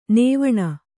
♪ nēvaṇa